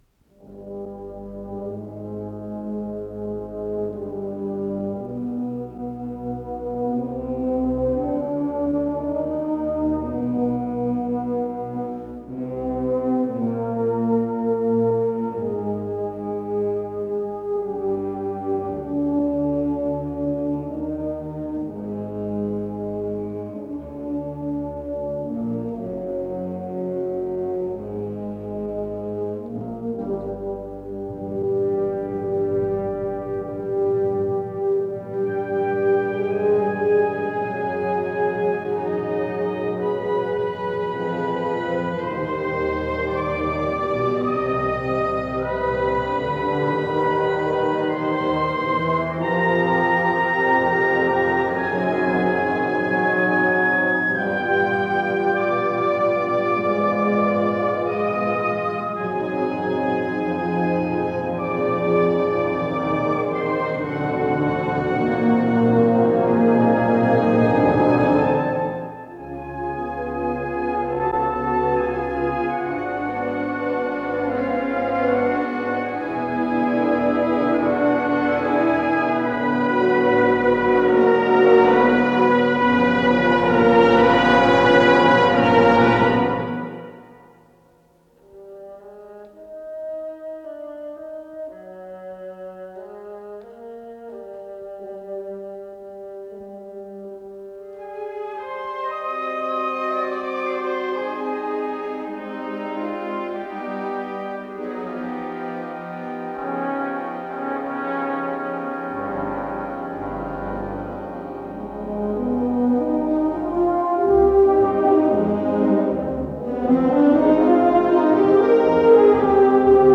Исполнитель: Образцово-показательный оркестр комендатуры Московского Кремля
До мажор